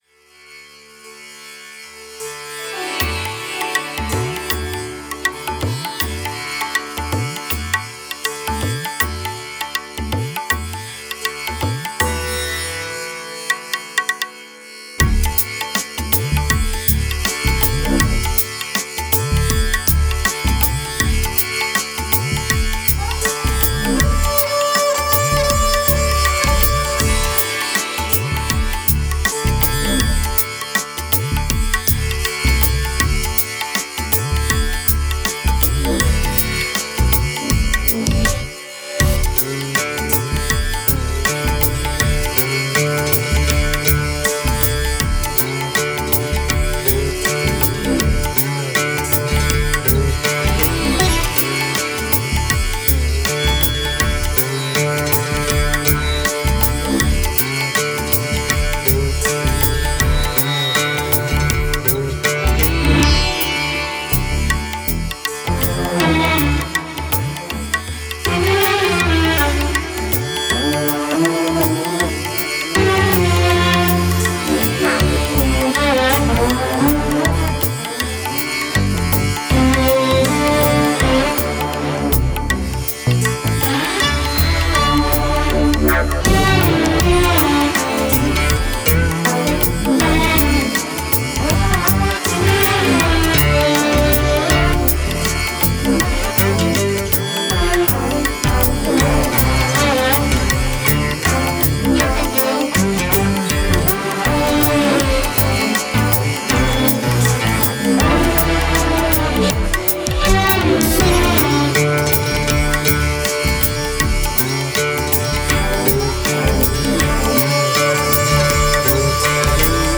موسیقی ماسالا
Indian-Masala-Music.mp3